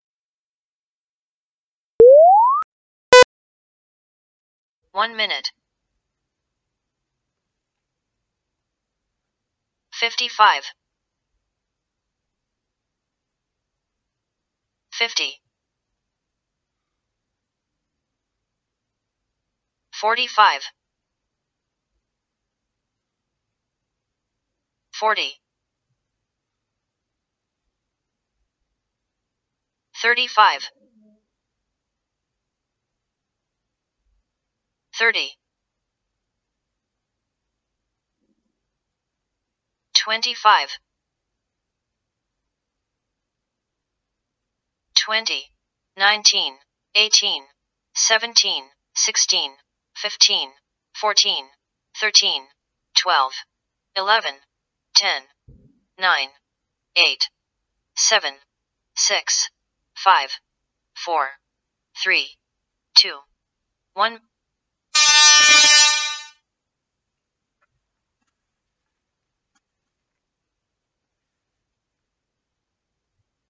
Start - countdown
Countdown1min(EN).mp3
countdown_EN_1min.mp3